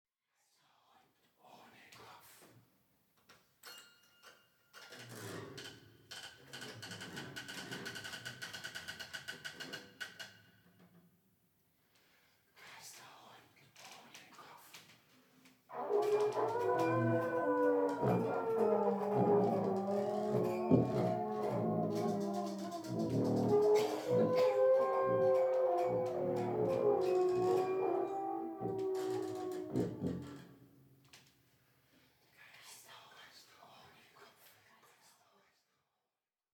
Klavier
Posaune
Euphonium